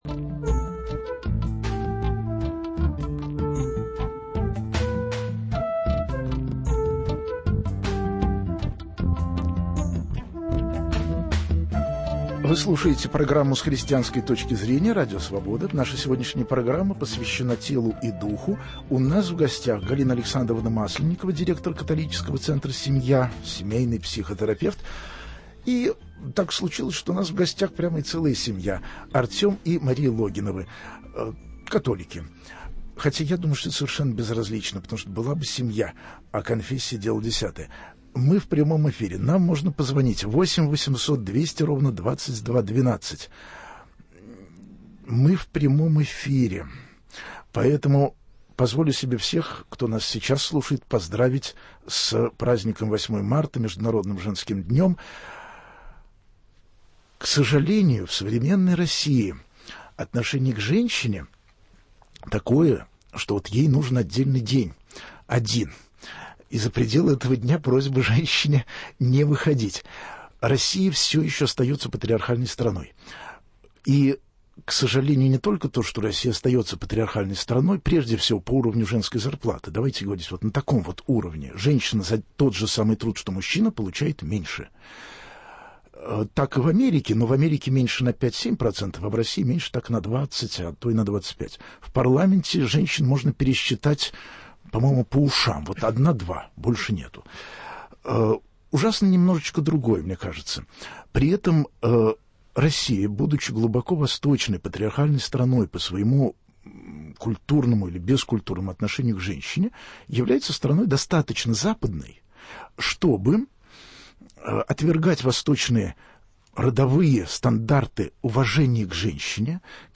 Разговор о телесном и духовном: чем и почему отношение верующего человека к своему телу, да и к чужому телу тоже: почему христиане называют распутством то, что многие неверующие люди считают нормальными физиологическими актами.